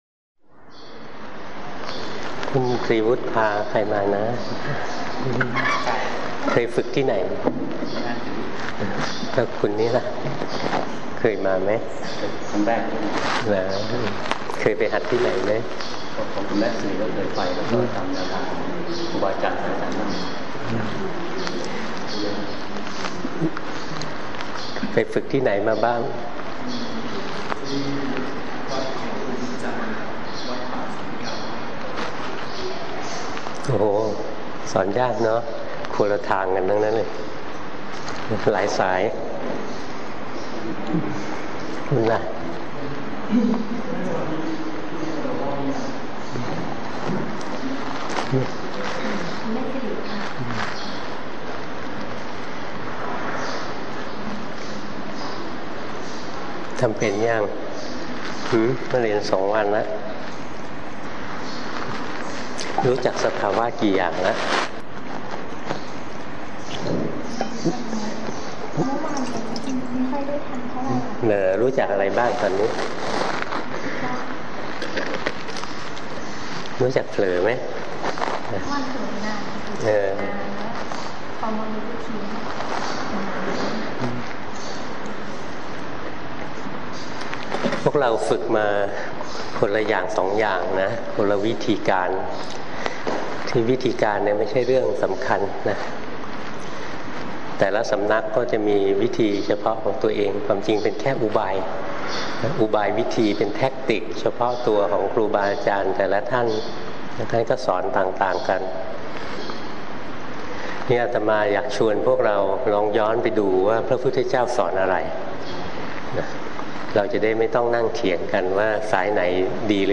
ธรรมเทศนาวันอาทิตย์ที่ 30 มีนาคม 2546(1) - พระอาจารย์ปราโมทย์ ปราโมชโช
| in พุทธศาสนา ไฟล์เสียง พระอาจารย์ปราโมทย์ ปราโมชโช สวนสันติธรรม จ.ชลบุรี 41:45 minutes (19.12 MB) » Download audio file 286 downloads 14 plays